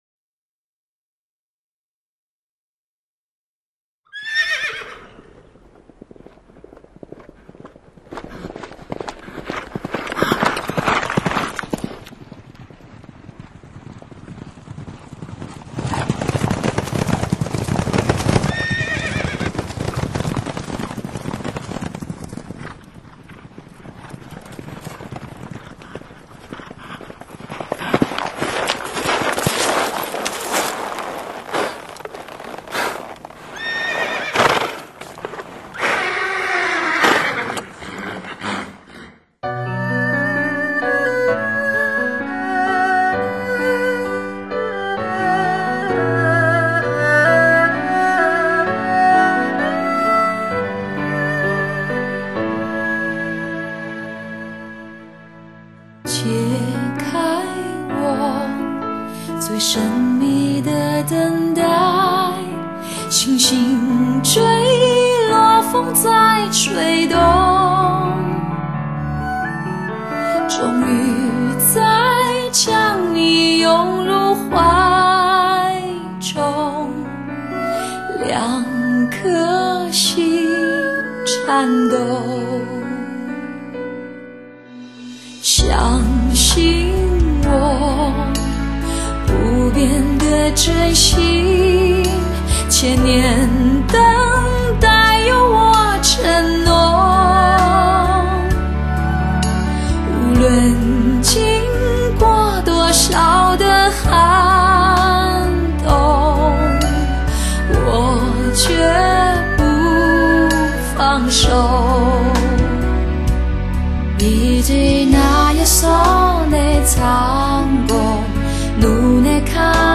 音色撩人